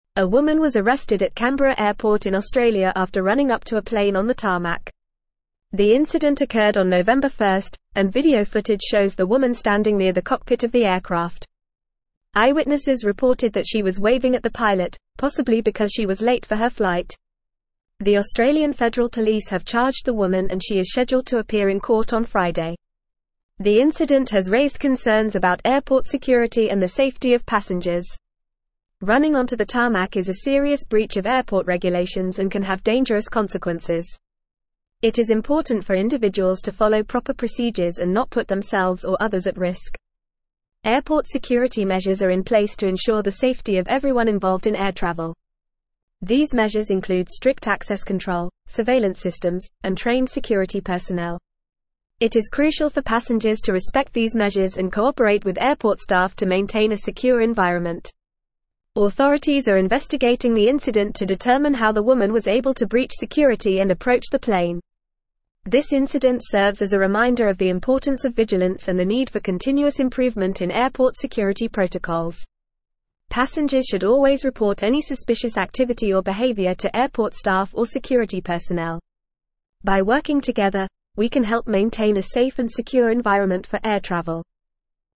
Fast
English-Newsroom-Advanced-FAST-Reading-Woman-Arrested-for-Running-onto-Tarmac-at-Canberra-Airport.mp3